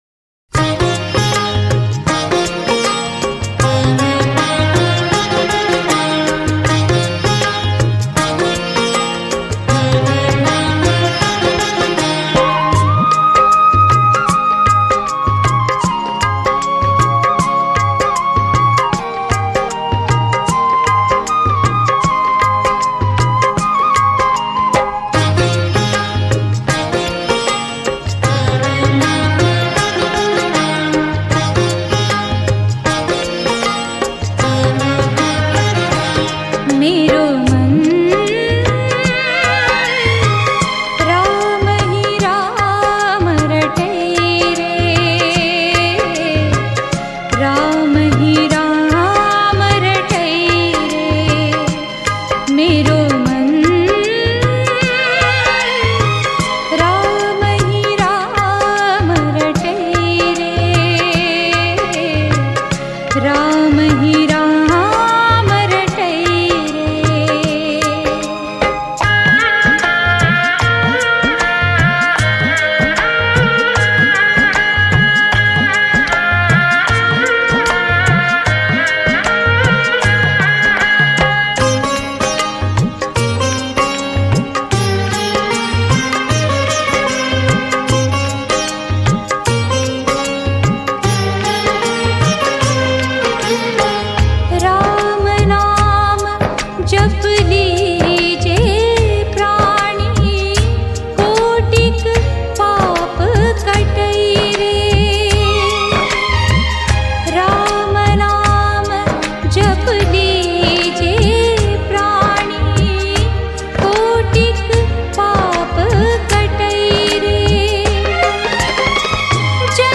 Types Of Song:Ram Bhajan